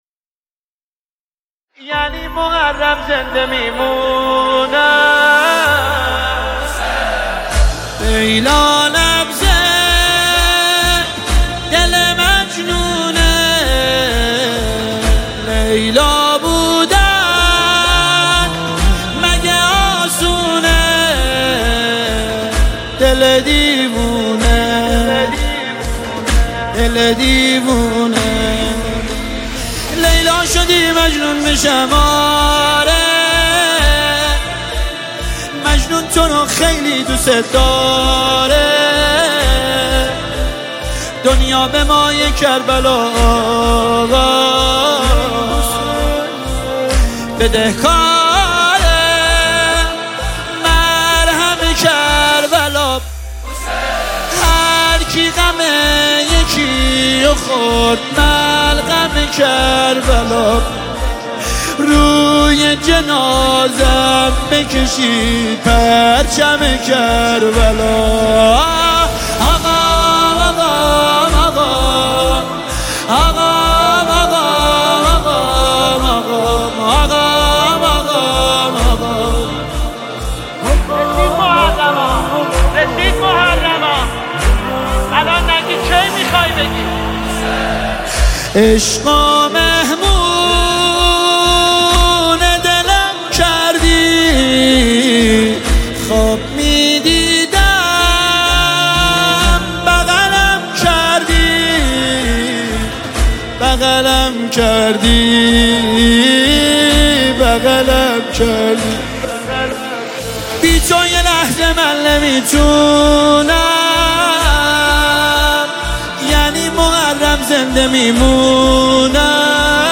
• نوحه و مداحی